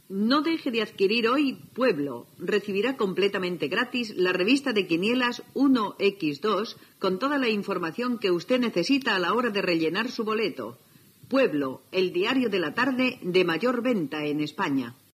Anunci de la "Revista de Quinielas" del Diario Pueblo